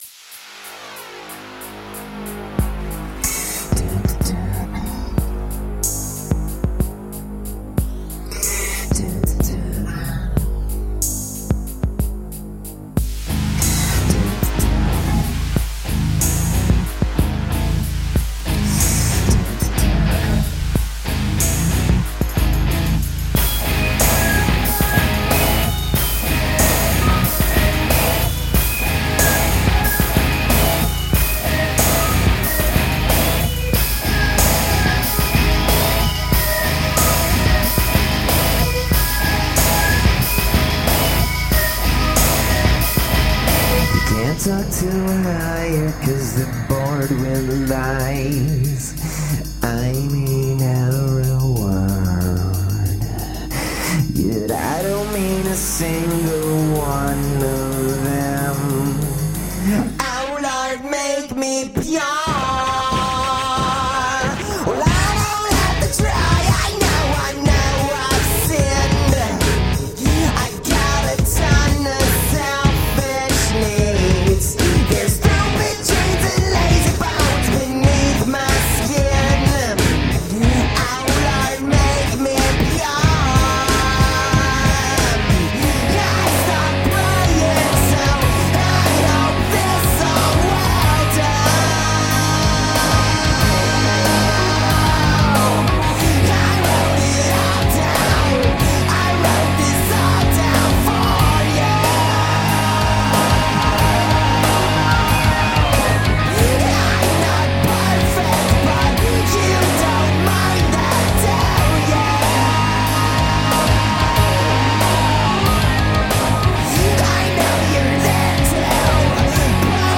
Guitar-edged industrial electrorock.
Tagged as: Hard Rock, Industrial